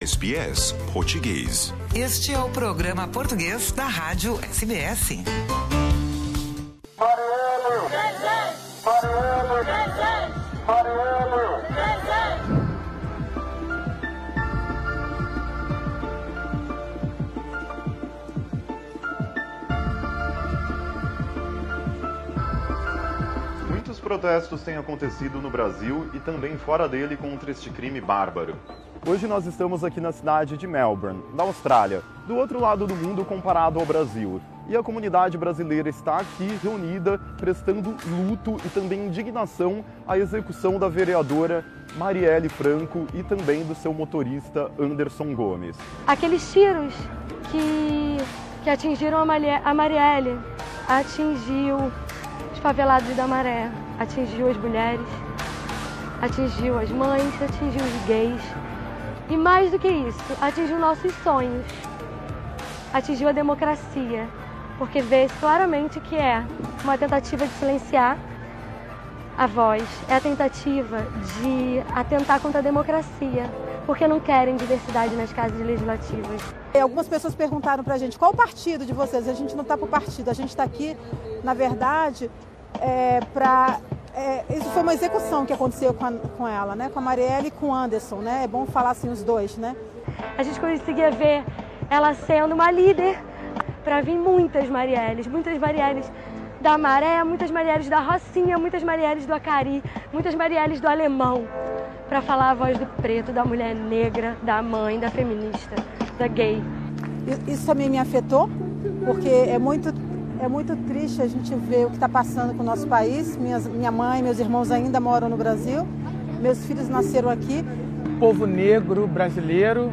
Protesto em Melbourne, 18 de março de 2018 Source